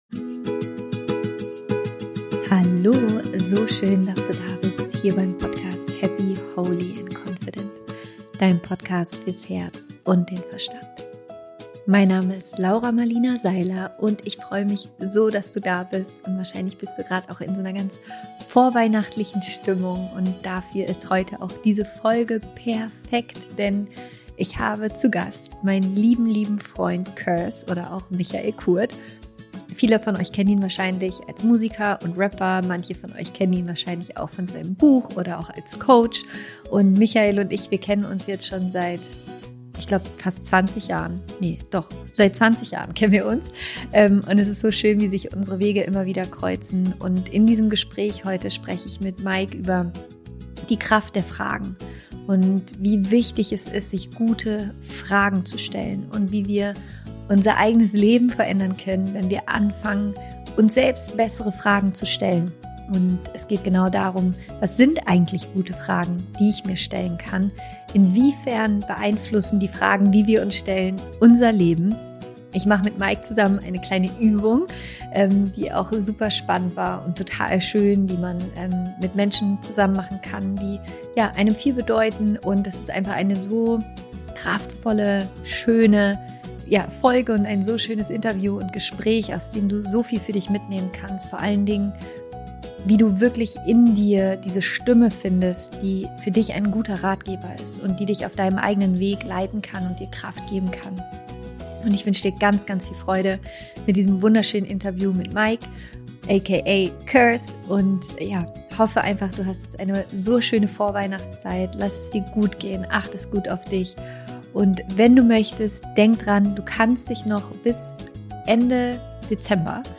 Die Kraft von richtig guten Fragen - Interview Special mit Curse